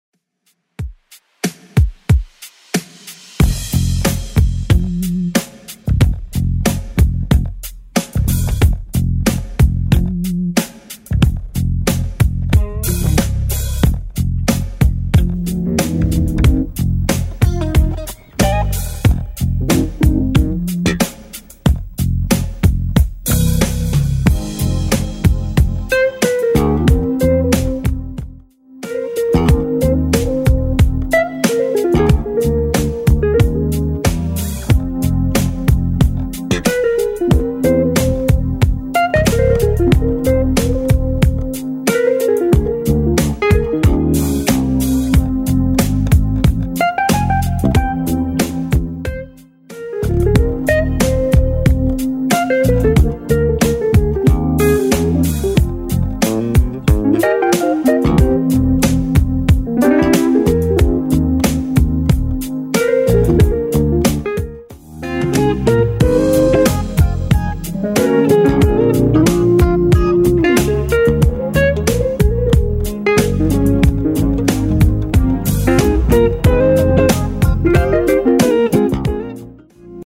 BPM: 92 Time